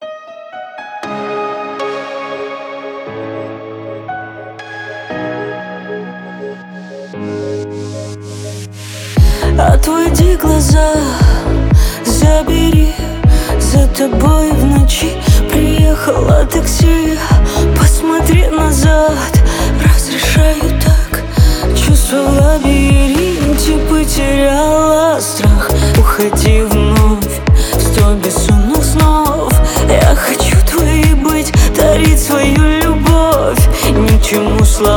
Pop in Russian Pop